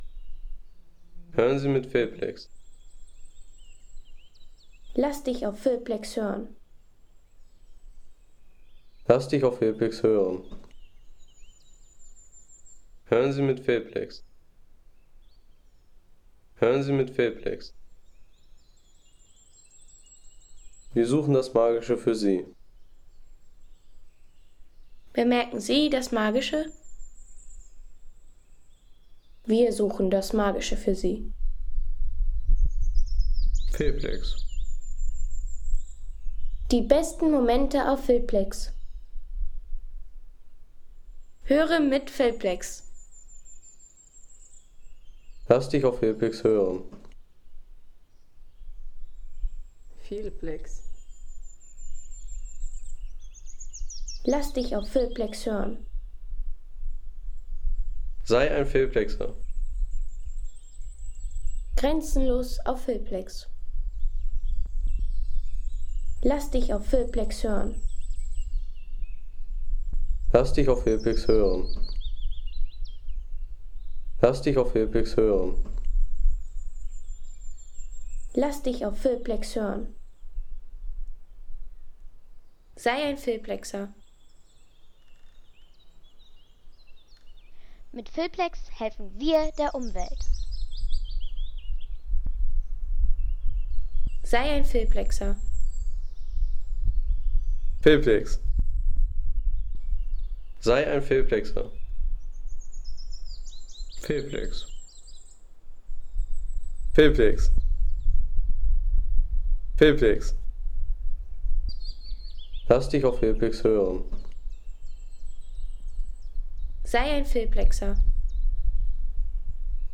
Landschaft Wälder